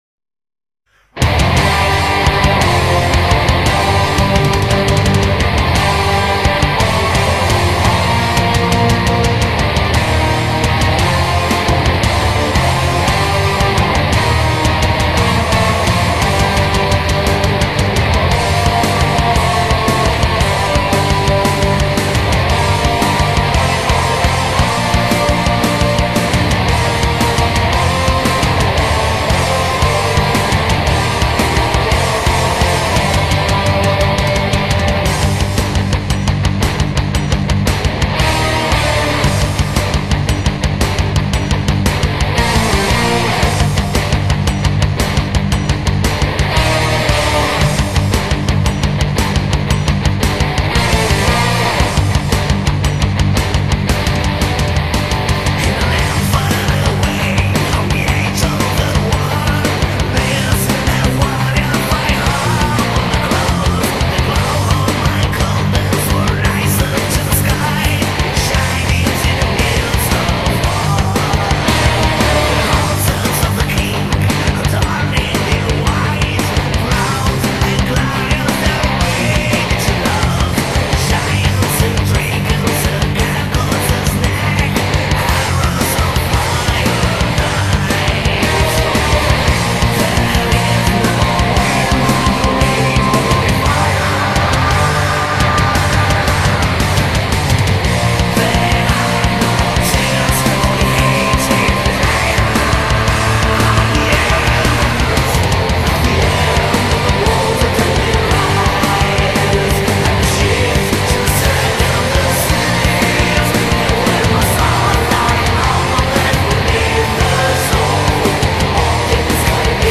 VIKING METAL ONE-MAN-BAND